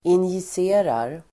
injicera verb, inject Grammatikkommentar: A & x Uttal: [injis'e:rar] Böjningar: injicerade, injicerat, injicera, injicerar Synonymer: inspruta Definition: ge en injektion Exempel: injicera lugnande medel (inject a sedative)